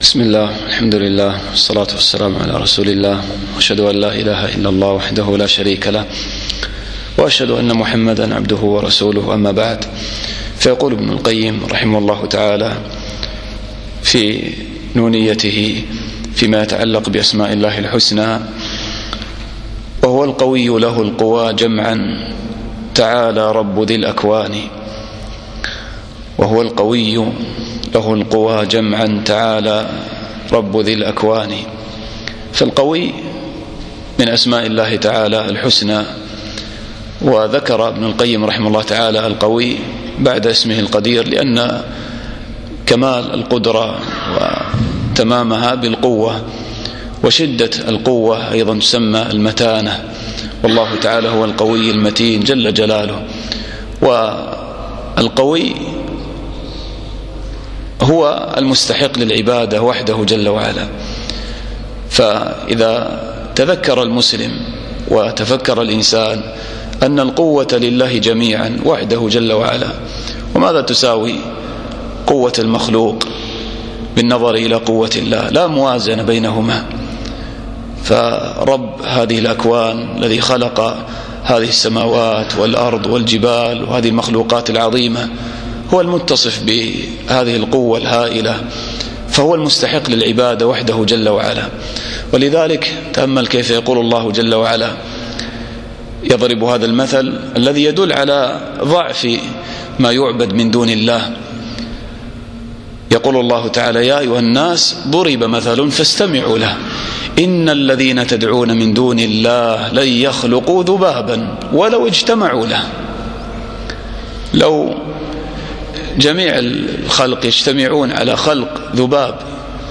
الدرس الرابع عشر